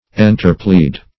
[1913 Webster] The Collaborative International Dictionary of English v.0.48: Enterplead \En`ter*plead"\, v. i. Same as Interplead .
enterplead.mp3